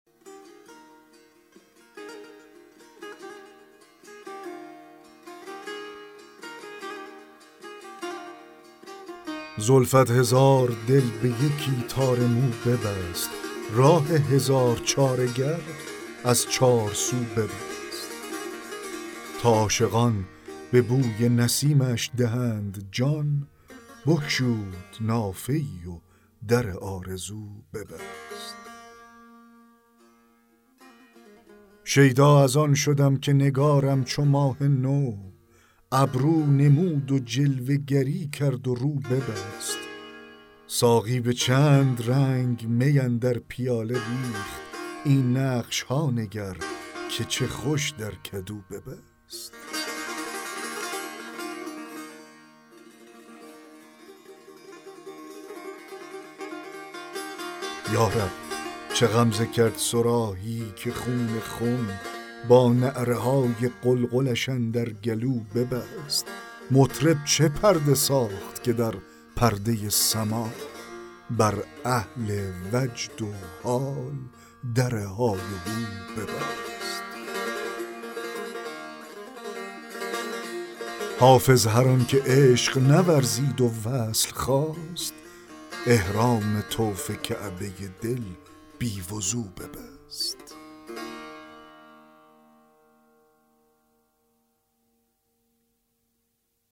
دکلمه غزل 30 حافظ
دکلمه غزل زلفت هزار دل به یکی تار مو ببست